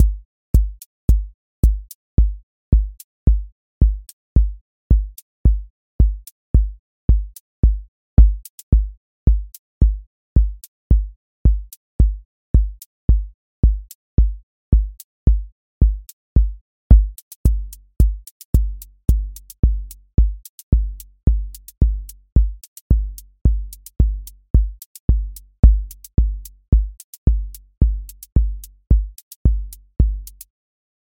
QA Listening Test house Template: four_on_floor
• voice_kick_808
• voice_hat_rimshot
• voice_sub_pulse
Steady house groove with lift return